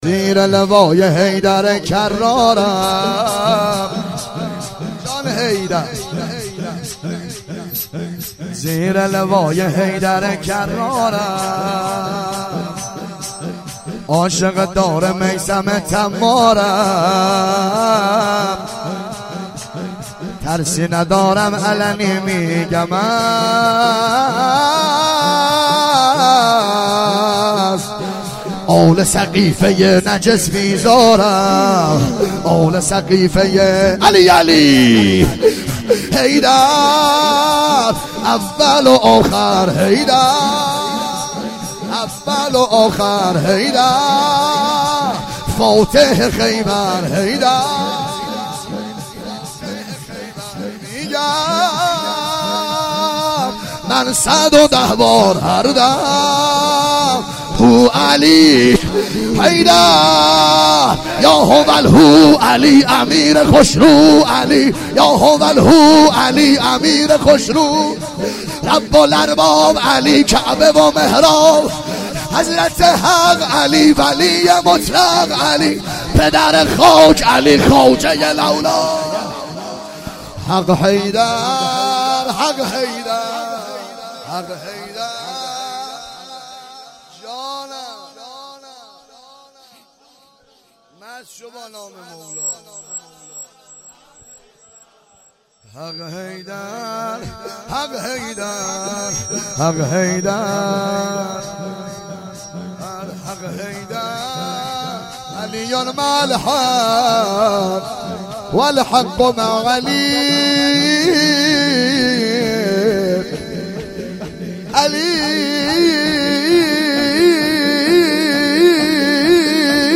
وفات حضرت معصومه-جمعه30آذر97